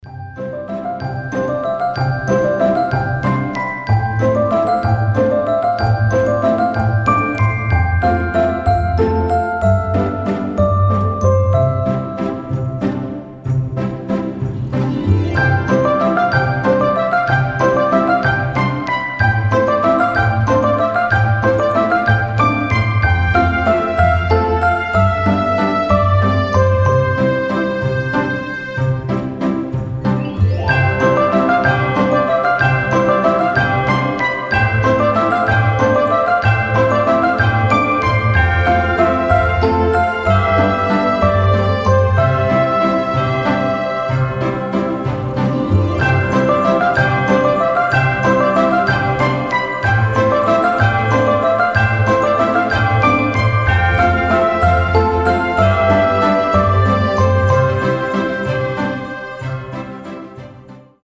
• Качество: 320, Stereo
красивые
без слов
добрые
праздничные
рождественские